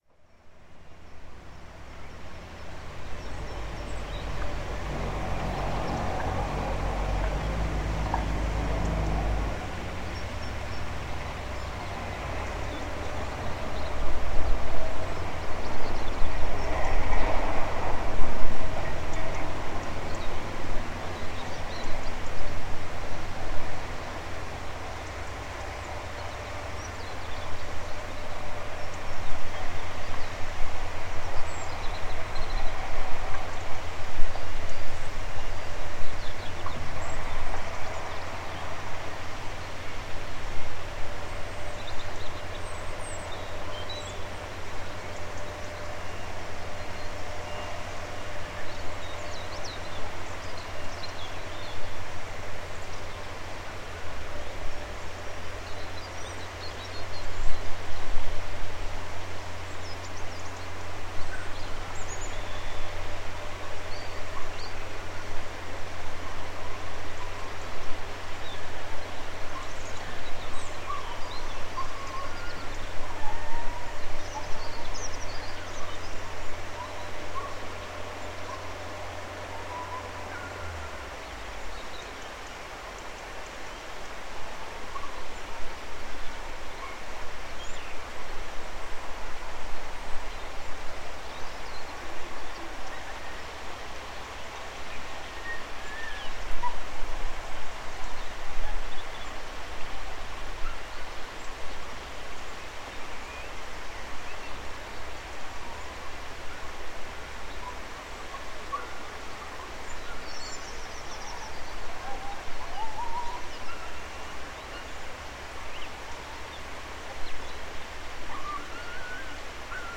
Gravação de uma paisagem sonora ao fim da tarde. Gravado com Fostex FR-2LE e um par de microfones shotgun Rode NTG-2
Tipo de Prática: Paisagem Sonora Rural
Portela-Paisagem-Sonora-de-Fim-de-Tarde.mp3